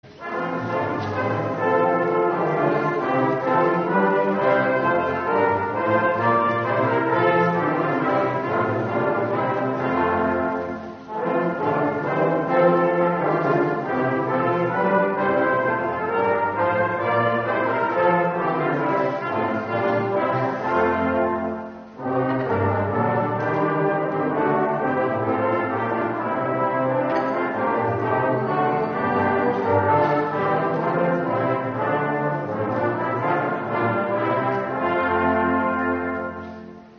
Konfirmationen in Ober- und Unterkochen – Posaunenchor Ebnat
Konfirmationen in Ober- und Unterkochen
01a_Intrade_Lobe_den_Herren.mp3